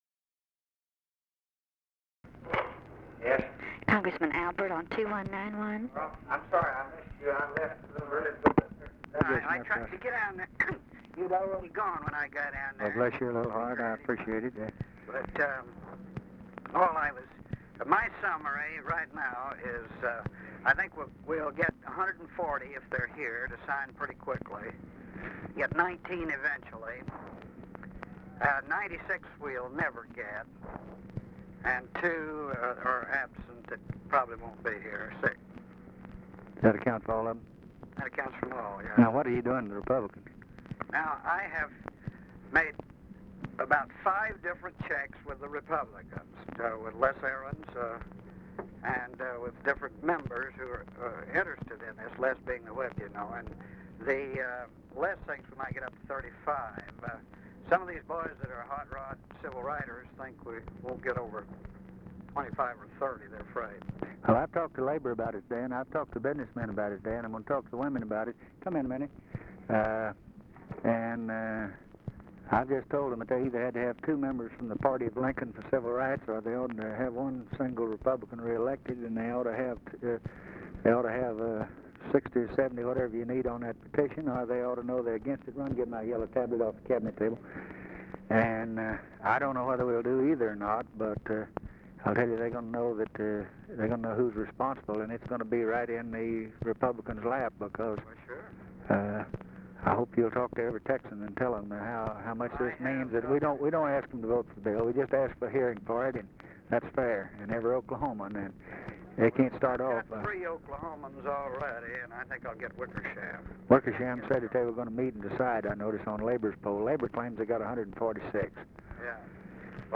Conversation with CARL ALBERT, December 4, 1963
Secret White House Tapes